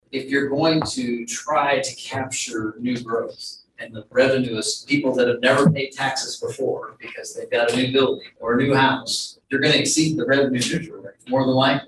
City Manager Ron Fehr spoke about some of the disadvantages of the new law.